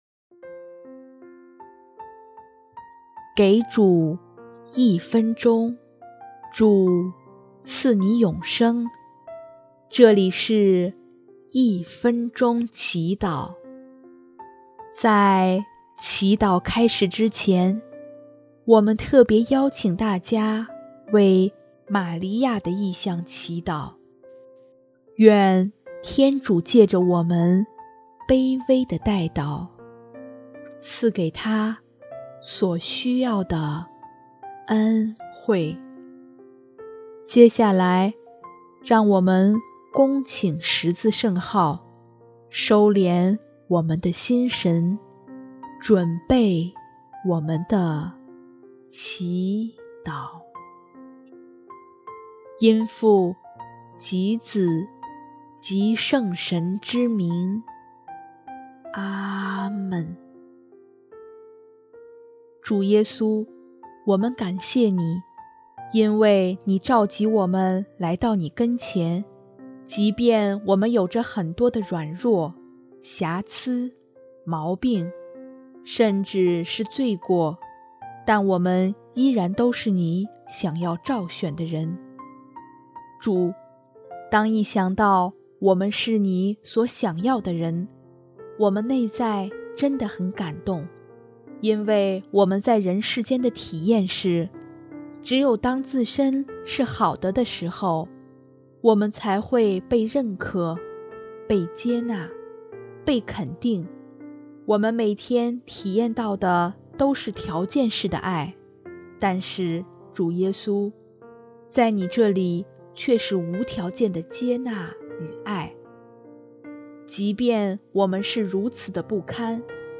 音乐： 第二届华语圣歌大赛参赛歌曲《爱生命喜悦》